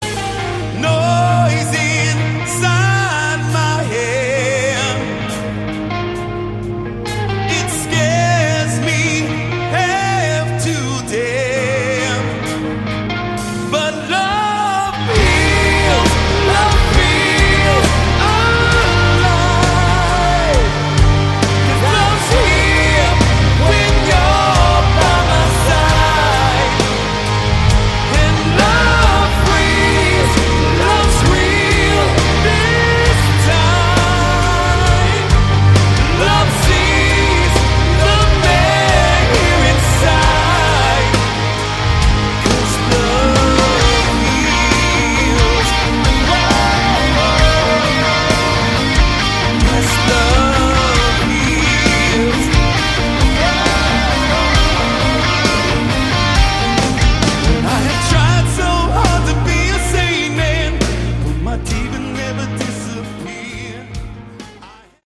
Category: Melodic Rock
lead vocals, keyboards
rhythm and lead guitars, backing and lead vocals
drums
bass